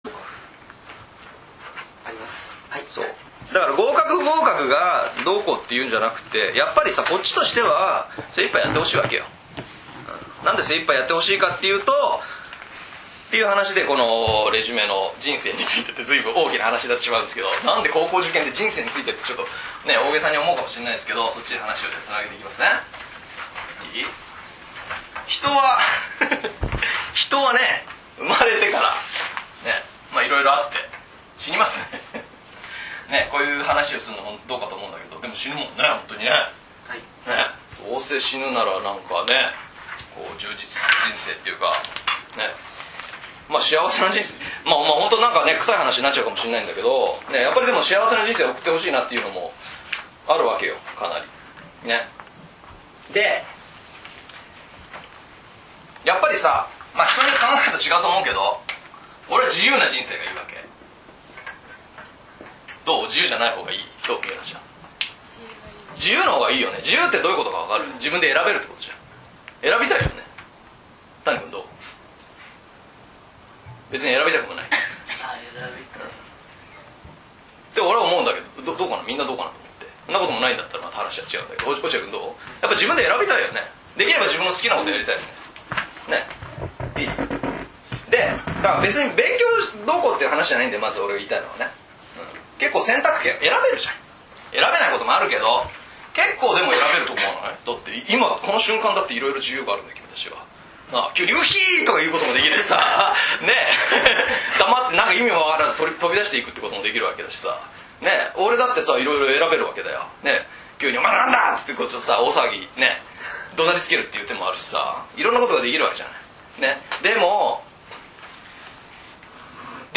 本気になれるセミナー音声
この音声は、私がおととしの９月に中学３年生を対象に開いた 「高校受験のための勉強法セミナー」 の一部です。 ・人生が終わる時に後悔したくないこと ・勉強はなぜ必要なのか ・夢や目標を叶えるための考え方 などについて、生徒をいじり倒しながら（笑）、熱く語っております。